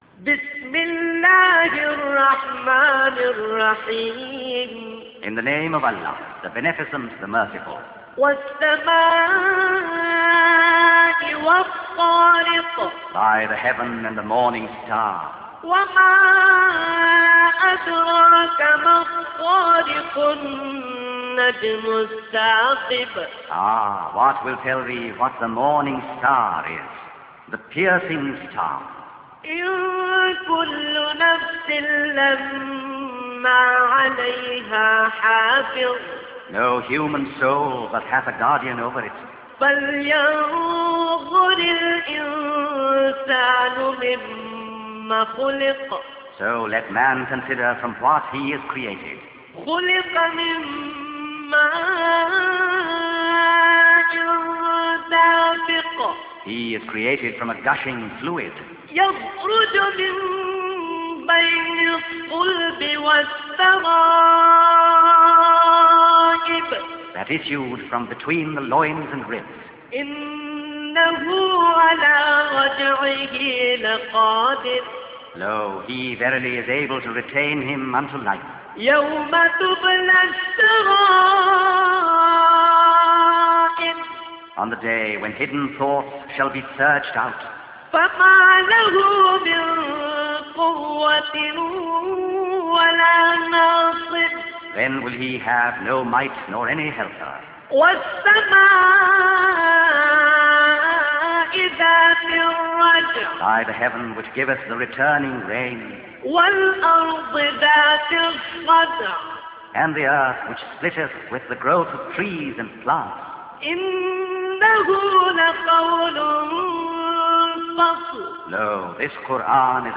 · Recitation of Quran